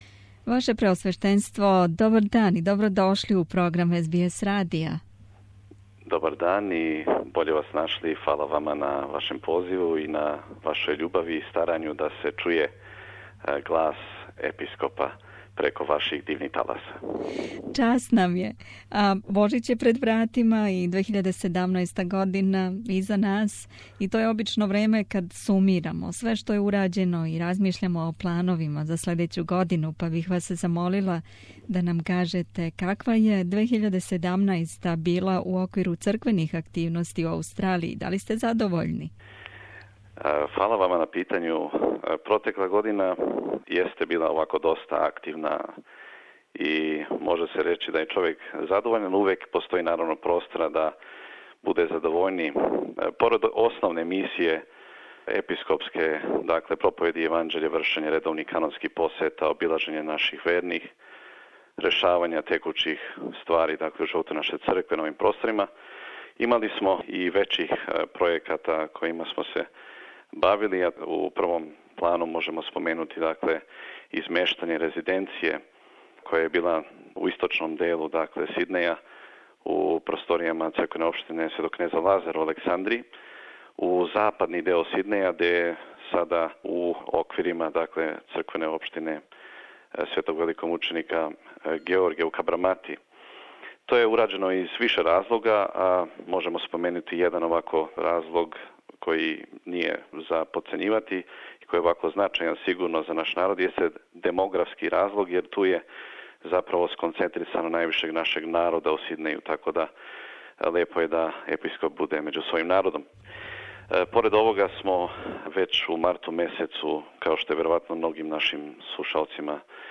intervju_vladika_siluan.mp3